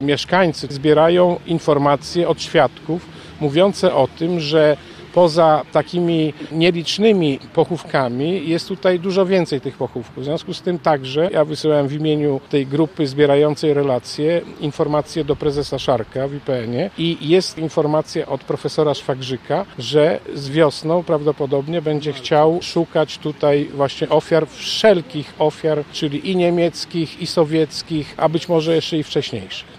Tymczasem wszystko wskazuje na to, że na tym terenie znajdują się pochówki. Będzie to przedmiotem badań – mówi przewodniczący klubu radnych PiS w Radzie Miasta Lublina, Piotr Gawryszczak.